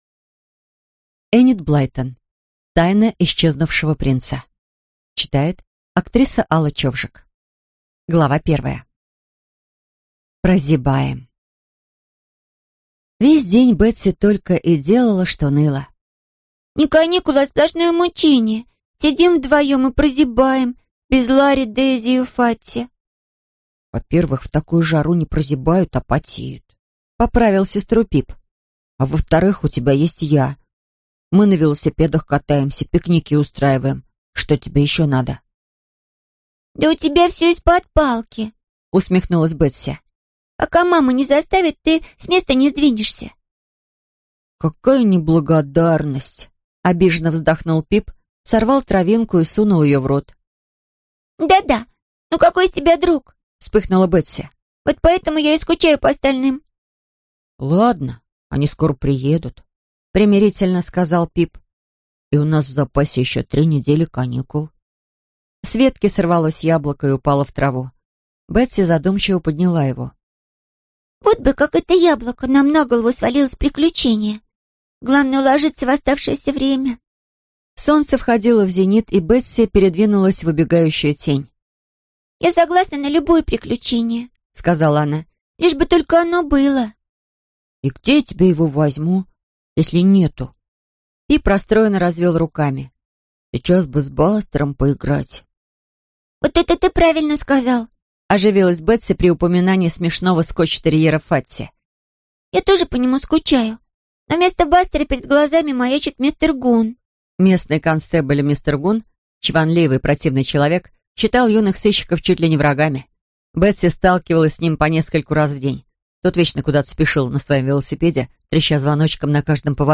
Аудиокнига Тайна исчезнувшего принца | Библиотека аудиокниг